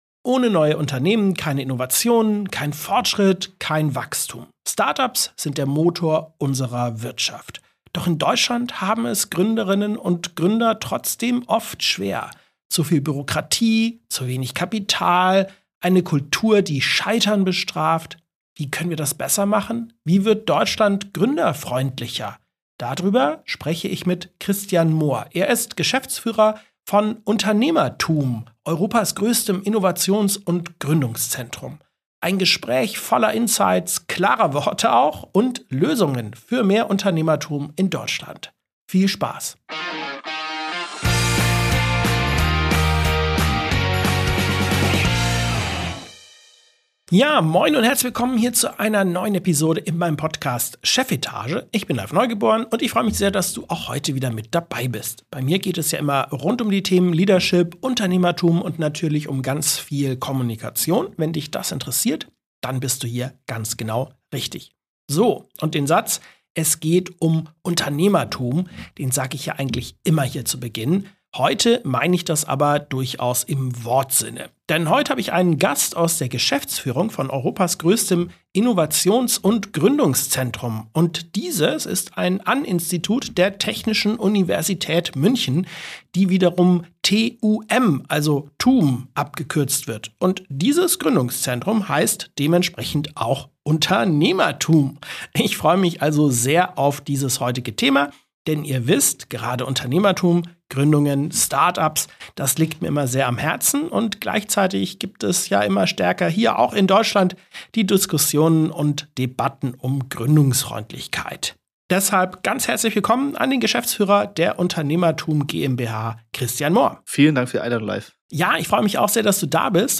Er erklärt, warum eine echte Gründerkultur mehr braucht als Kapital und welche Rolle Hochschulen, Investoren und die Politik dabei spielen. Über Chefetage Chefetage ist der Podcast rund um Leadership, Unternehmertum und Kommunikation. Wir tauschen uns mit CEOs, Unternehmern und Führungspersönlichkeiten über Erfolgsstrategien, effektive Führung, Kommunikation und die neuesten Business-Trends aus.